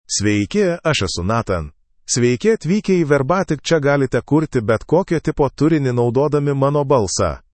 NathanMale Lithuanian AI voice
Nathan is a male AI voice for Lithuanian (Lithuania).
Voice sample
Listen to Nathan's male Lithuanian voice.
Male
Nathan delivers clear pronunciation with authentic Lithuania Lithuanian intonation, making your content sound professionally produced.